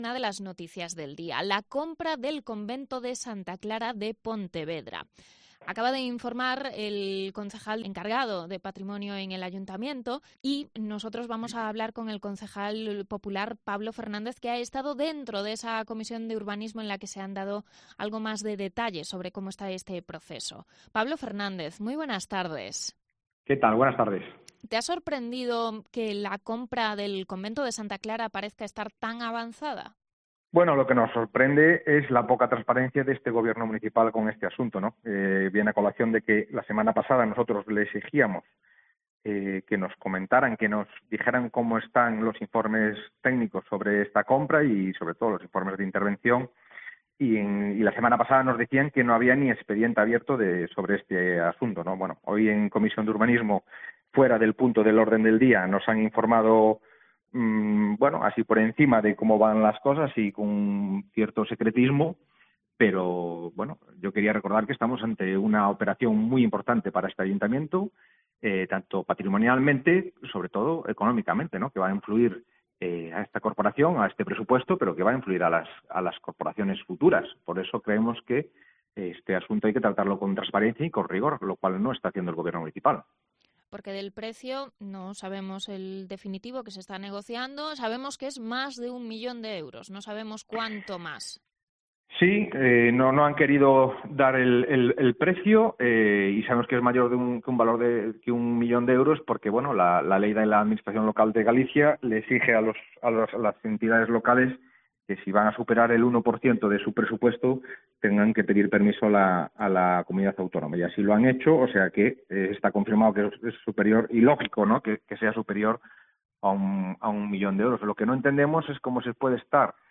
Entrevista con el concejal Pablo Fernández sobre la compra del convento de Santa Clara de Pontevedra